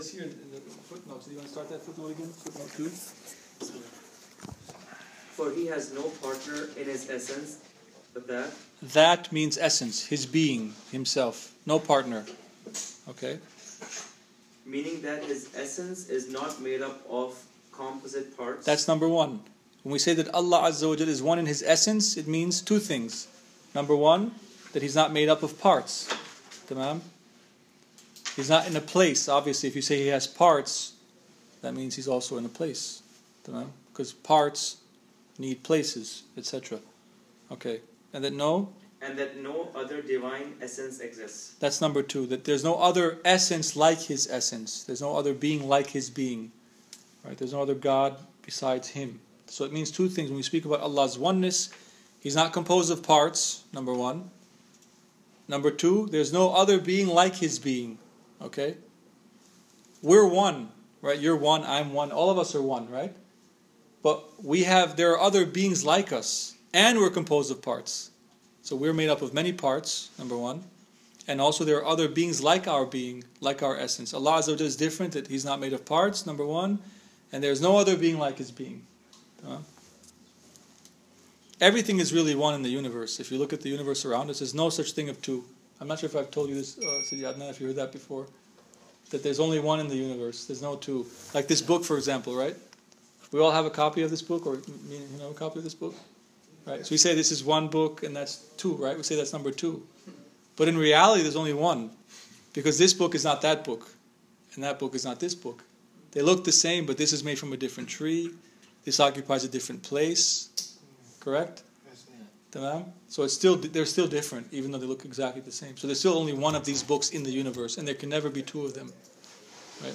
Class 1